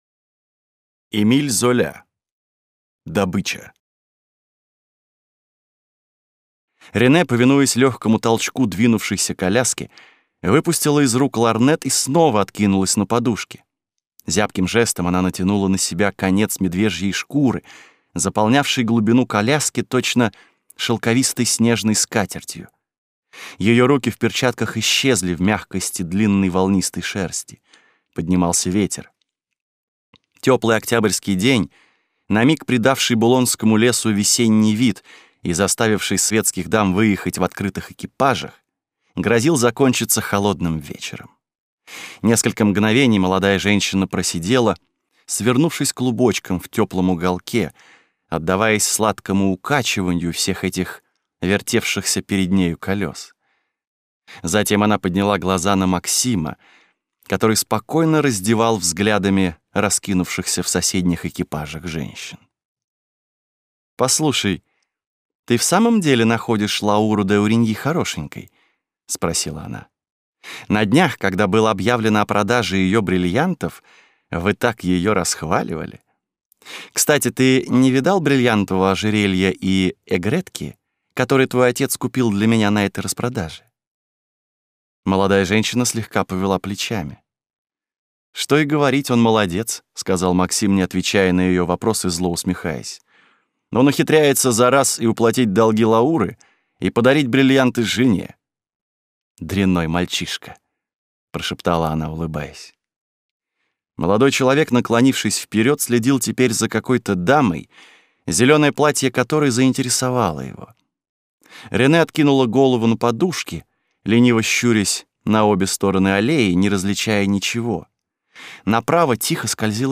Aудиокнига Добыча